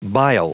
Prononciation du mot bile en anglais (fichier audio)
Prononciation du mot : bile